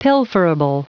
Prononciation du mot pilferable en anglais (fichier audio)
Prononciation du mot : pilferable